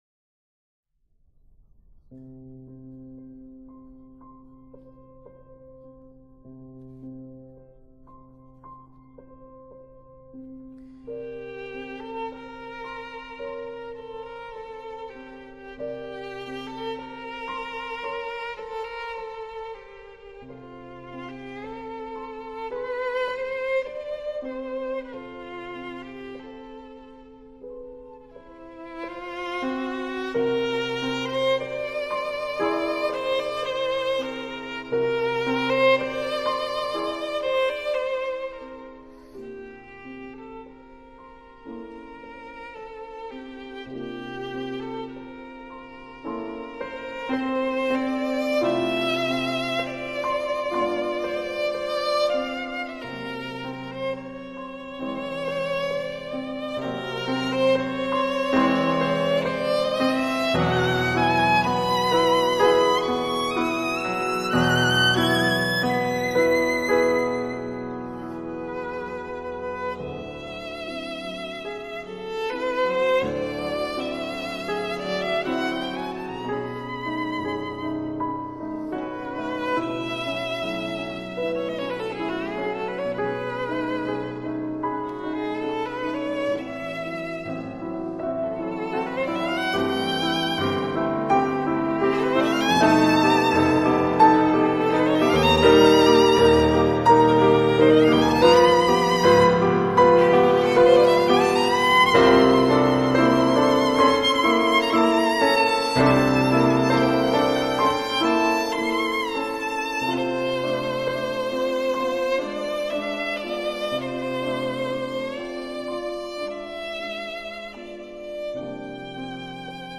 Nocturne pour violon et piano
Lili-Boulanger-Nocturne-pour-violon-et-piano-1911-mp3cut.net_.m4a